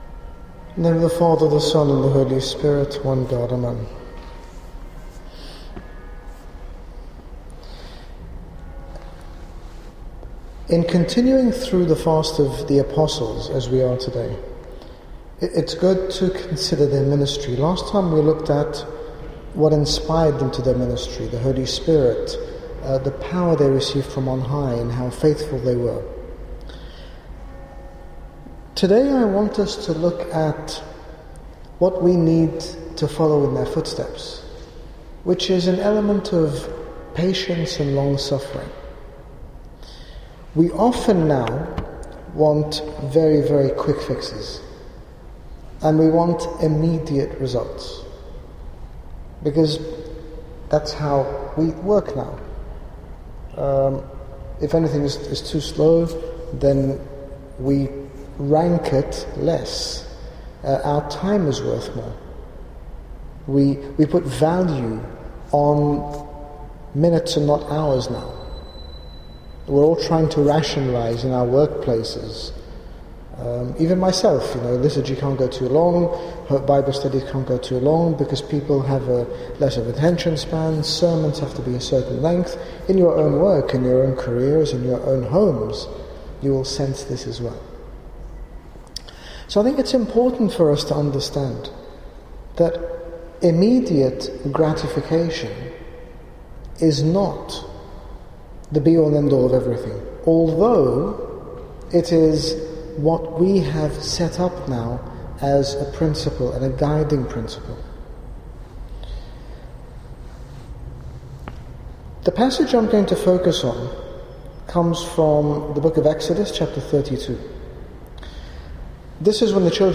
In this talk, His Grace Bishop Angaelos speaks about the importance of being patient in our spiritual lives, ensuring that we do not replace God with other 'gods' that cannot satisfy us. We often attempt to fill the void we experience when we don't feel God's presence in our lives, but His Grace warns of the detrimental effect this will have on our sense of identity and on our lives in general.